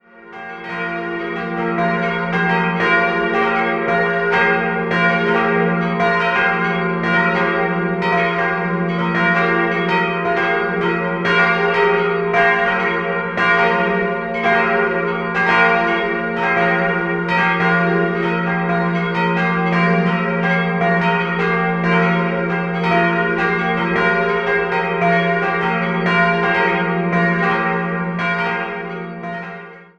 4-stimmiges Geläut: f'-g'-b'-des''' Die Glocke stammt von Rincker aus dem Jahr 2017, die beiden mittleren von Karl Czudnochowsky aus dem Jahr 1951 und zur historischen kleinen Sterbeglocke liegen keine weiteren Angaben vor.